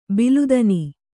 ♪ bilu dani